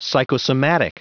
Prononciation du mot psychosomatic en anglais (fichier audio)
Prononciation du mot : psychosomatic